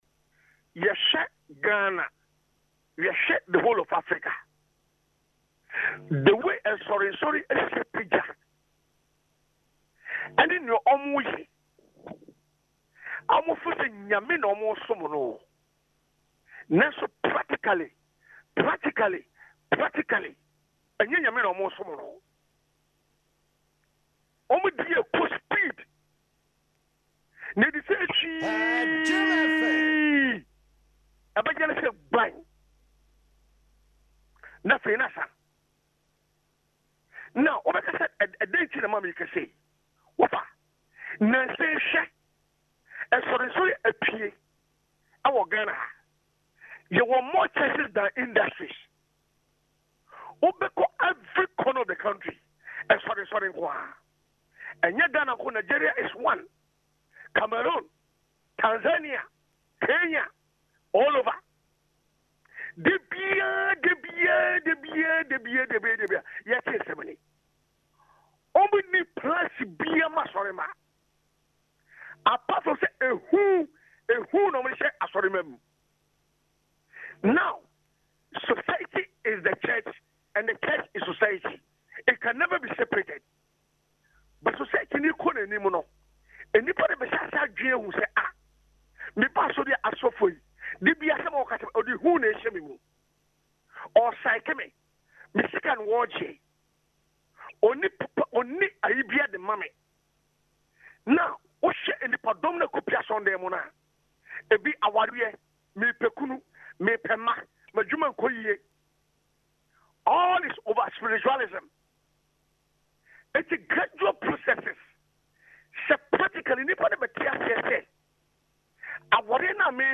Adom FM’s flagship programme, Dwaso Nsem